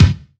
Index of /90_sSampleCDs/EdgeSounds - Drum Mashines VOL-1/M1 DRUMS